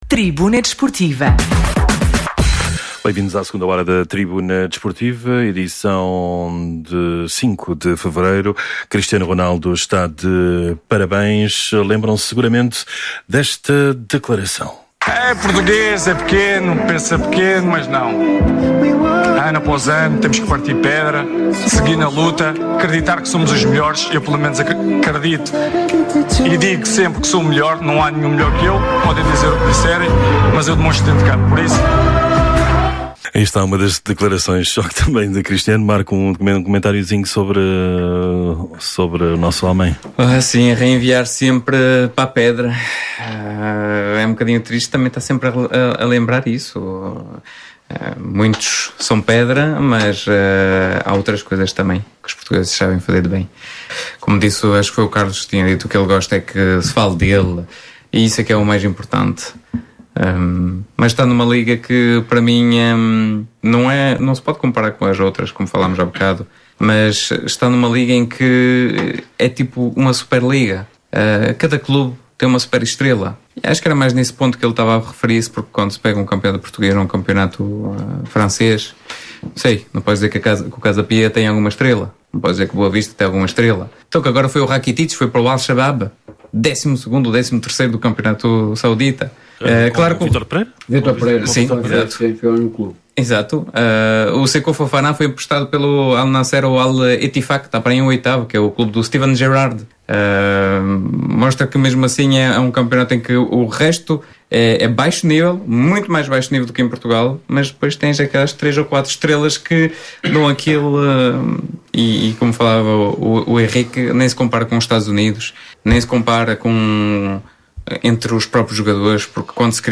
Atualidade Desportiva, Entrevistas, Comentários, Crónicas e Reportagens.
Tribuna Desportiva é um programa desportivo da Rádio Alfa e um dos mais antigos da nossa Rádio.